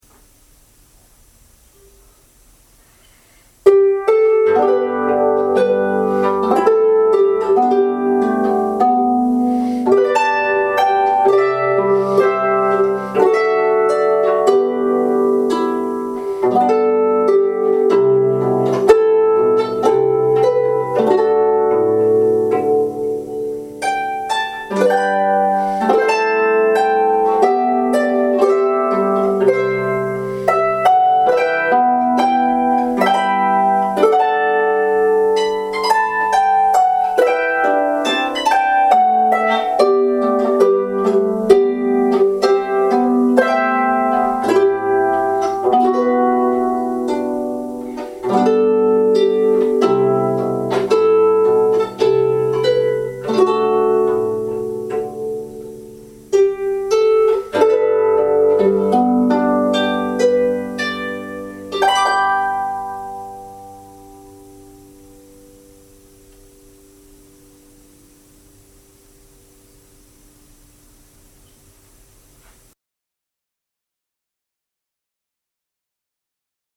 45” high 32 nylon strings, from G (1 1/2 octaves below middle C) up to C Retails new at $4000 Includes tuning key, padded cover, music
Eleanor Plunkett on Folk Harp.mp3